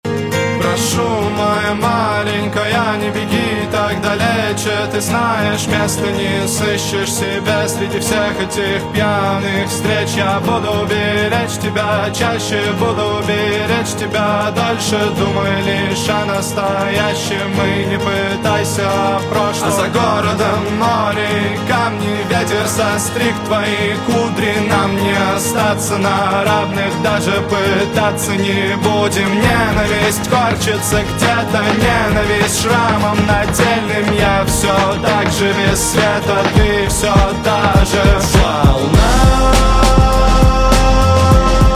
Русские Жанр: Поп Просмотров